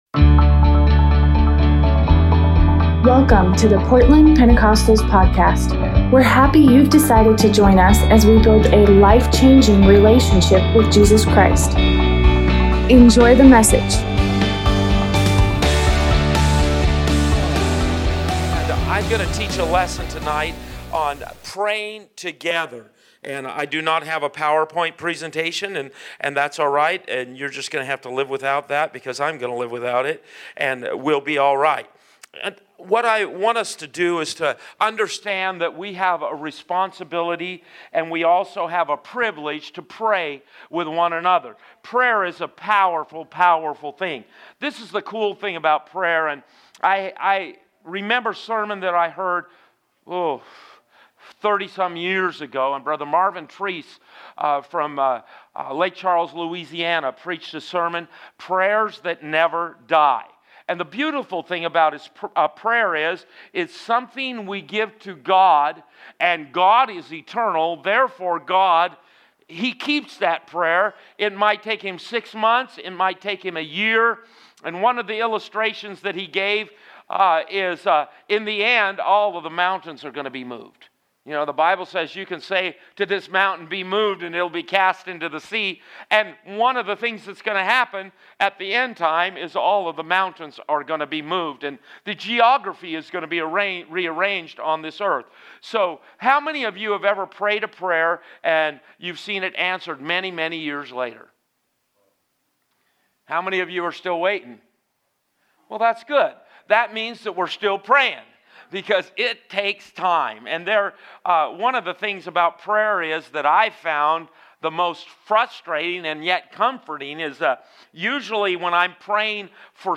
Tuesday Bible study
2020 Praying the Prayer Together Preacher